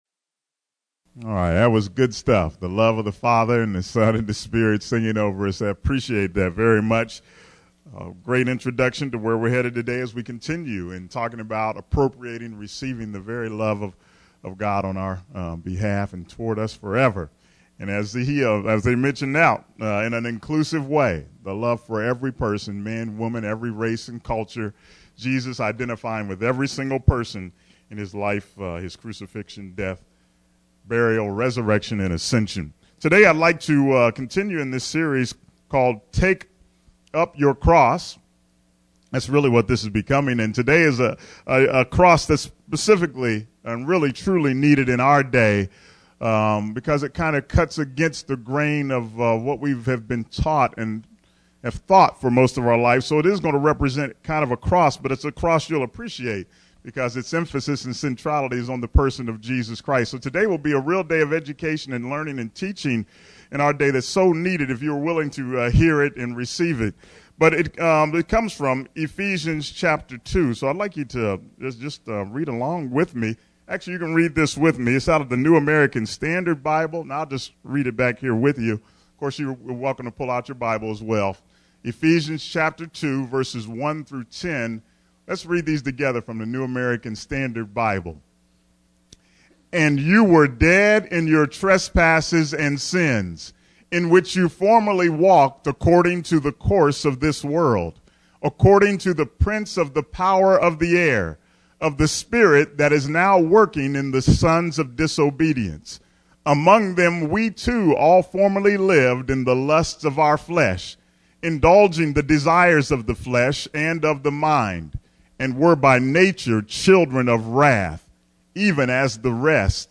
Sermon: Jesus – God’s Relationship Solution (NOT Penal Substitution!)